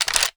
m82_boltback.wav